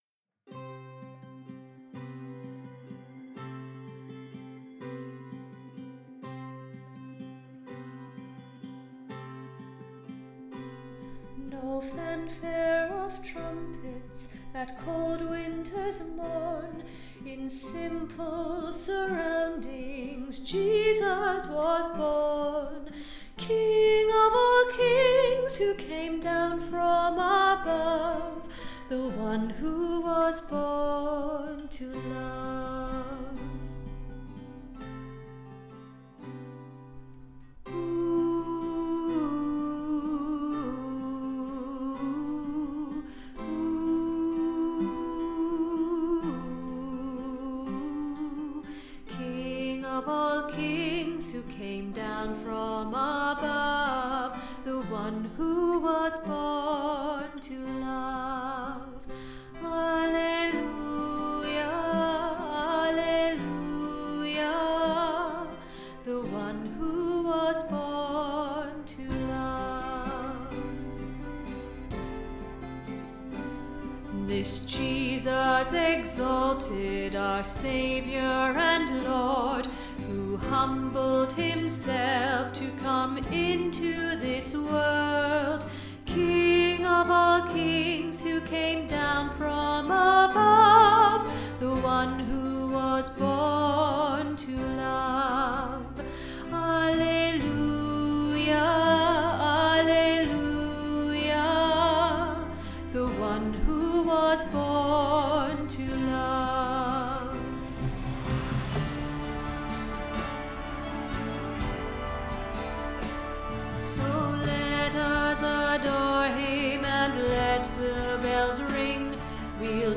The One Who Was Born To Love Tenor - Three Valleys Gospel Choir
The One Who Was Born To Love Tenor